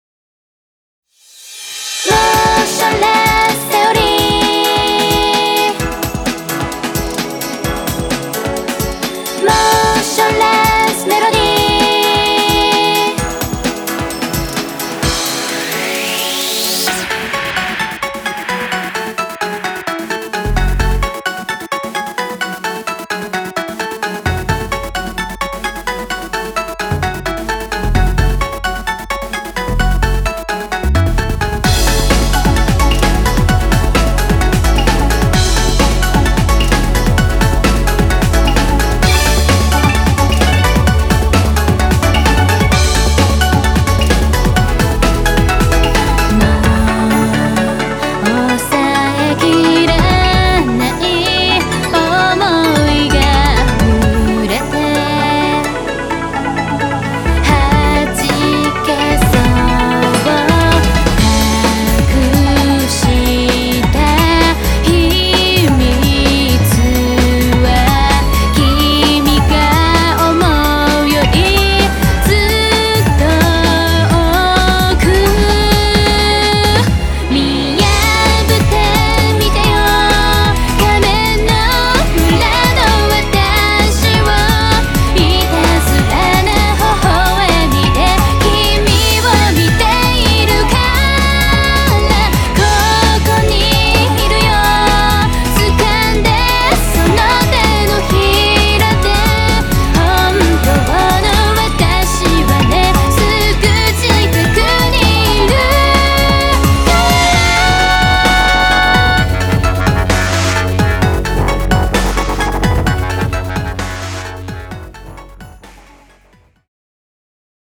東方フルボーカルアレンジアルバム 第六弾！！
情け無用のココロ×ポップス！